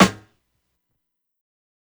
SNARE_BOUTTIME.wav